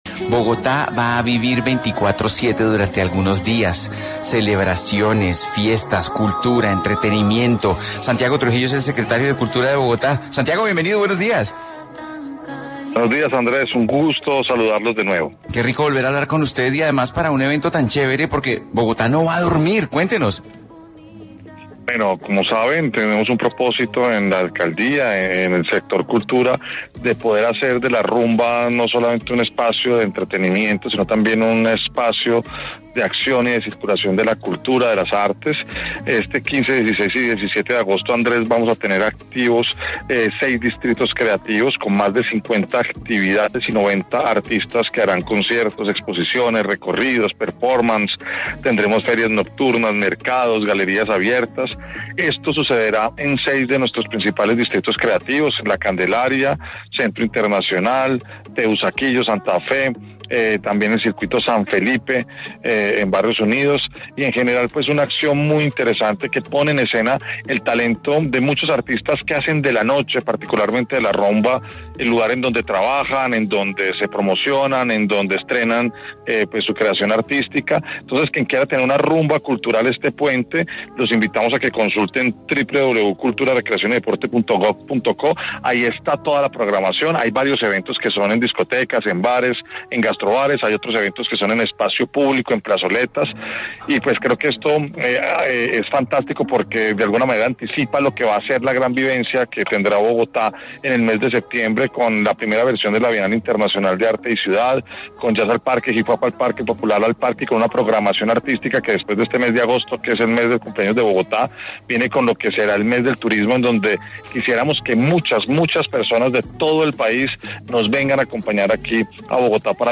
Así lo explicó para A Vivir Que Son Dos Días en Caracol Radio Santiago Trujillo, secretario de Cultura, quien resaltó la importancia de garantizar escenarios gratuitos y accesibles para todos.